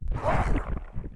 Index of /App/sound/monster/chaos_ghost
walk_act_1.wav